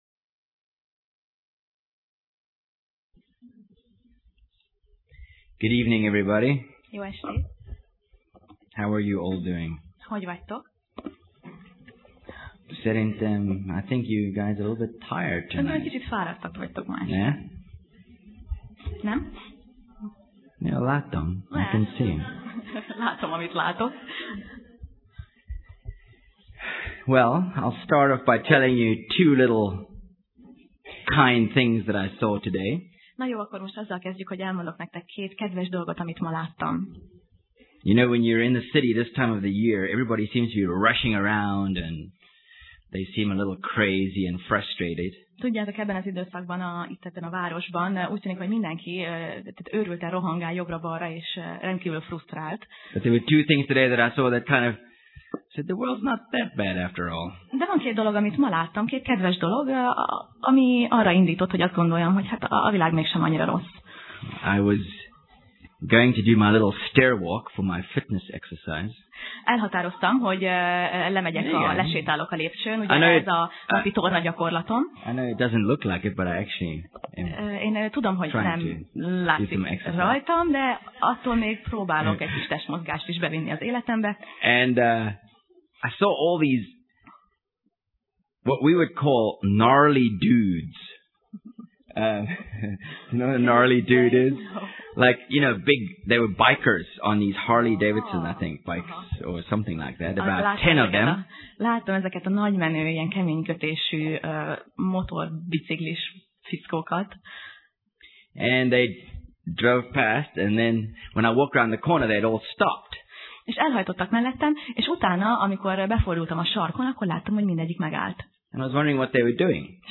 Zsidók Passage: Zsidók (Hebrews) 6:1–9 Alkalom: Szerda Este